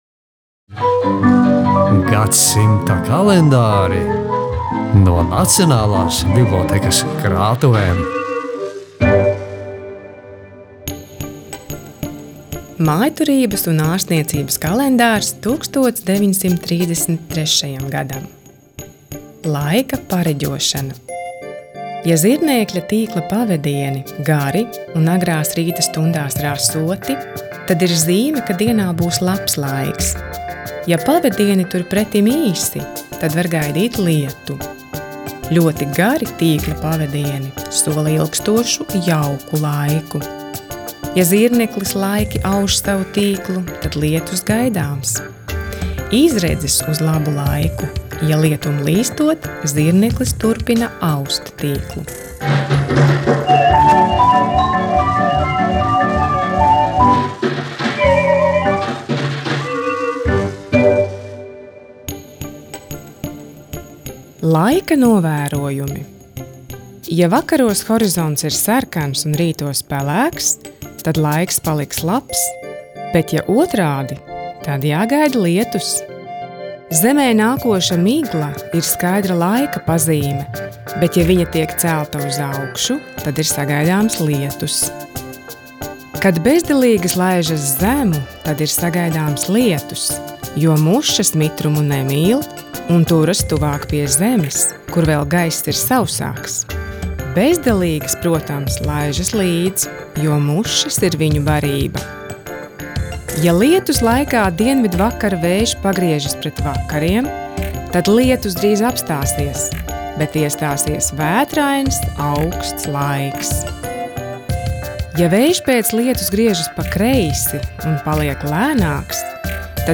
"Gadsimta kalendāri" ir Latvijas Nacionālās bibliotēkas un Latvijas Radio kopprojekts – audio stāstu rubrika, kurai bibliotēkas darbinieki ieskaņojuši LNB krātuvēs glabātu senu kalendāru sadzīves padomus. 2019. gada aprīlī un maijā tie ik rītu bija dzirdami Latvijas Radio rīta programmā "Labrīt".